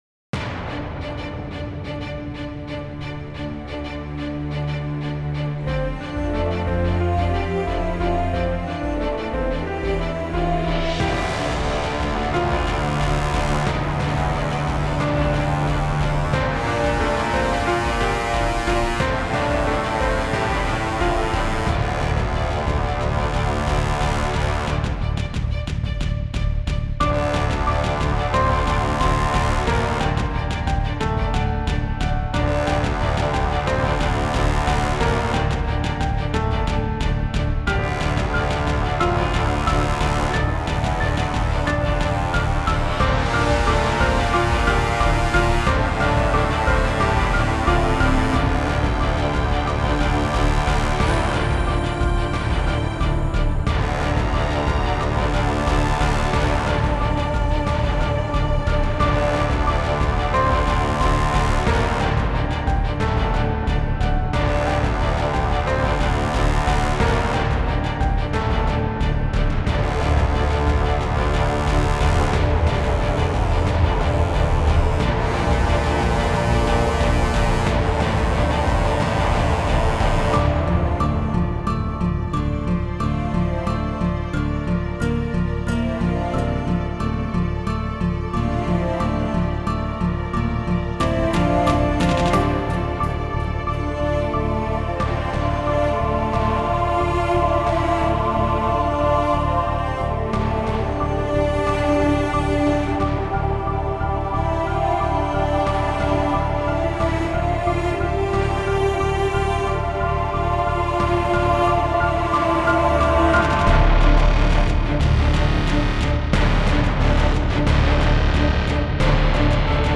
Will you? 212 Views 0 Favorites 0 Comments General Rating Category Music / All Species Unspecified / Any Gender Any Size 50 x 50px File Size 7.38 MB Keywords orchestral choir epic escape tense cinematic orch-synth music